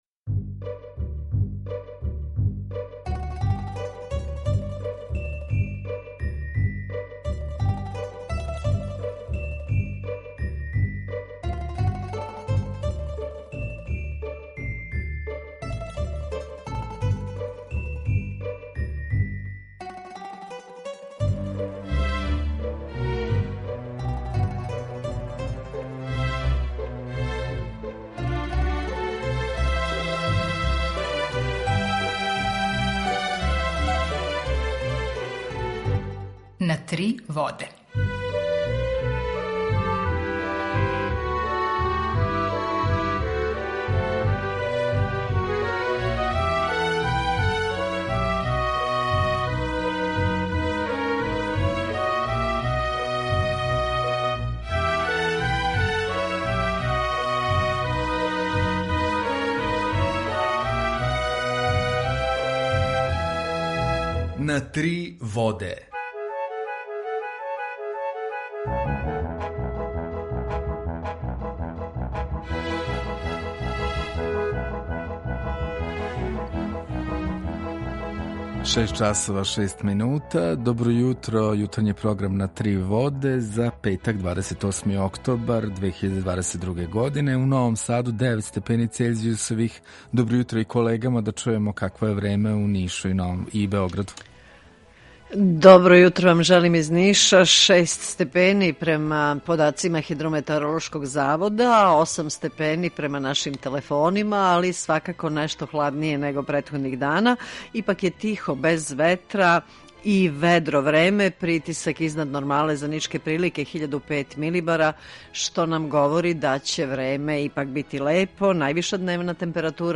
Емисију уживо реализујемо заједно са Радиом Републике Српске у Бањалуци и Радиом Нови Сад.
У два сата, ту је и добра музика, другачија у односу на остале радио-станице.